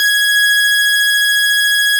Added more instrument wavs
snes_synth_080.wav